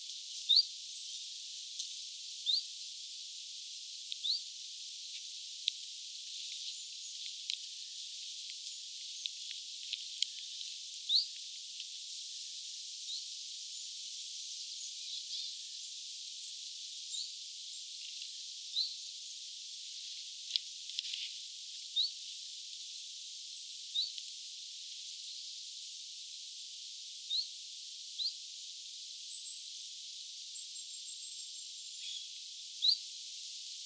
Parvessa oli sellaisia lintuja joiden ääni
sisältää b- ja z-kirjaimet läheltä kuultuna.
hyit, vit ja bizt.
tuollaisia ääniä tiltalttilinnuilla ainakin
tuollaisia_tiltalttilintujen_aania_1.mp3